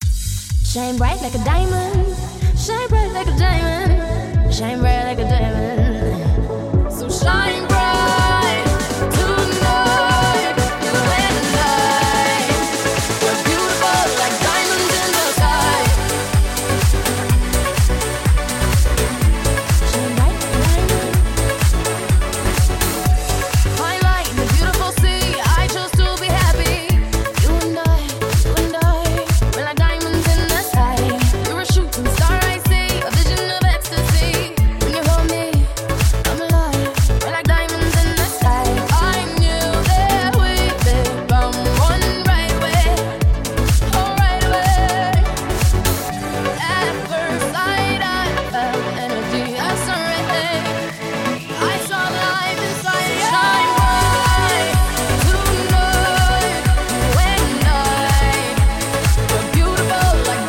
Marimba Remix